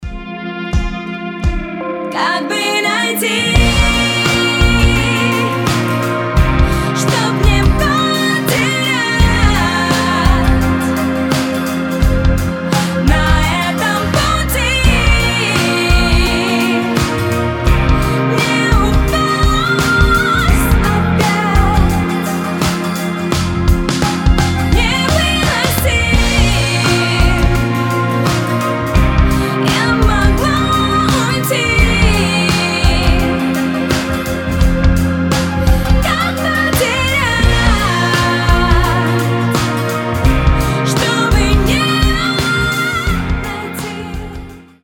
• Качество: 320, Stereo
поп
женский вокал
грустные